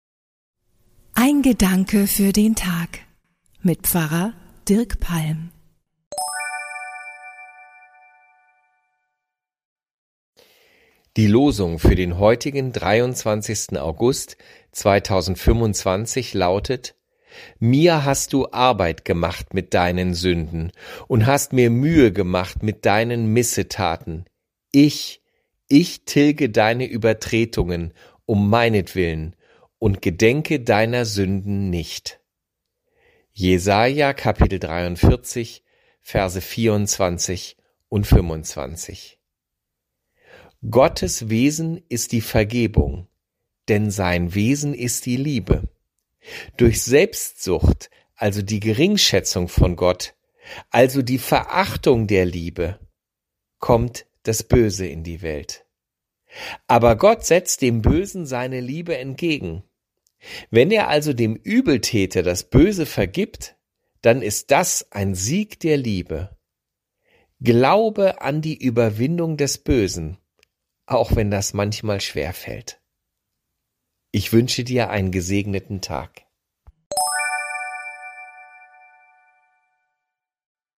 Pfarer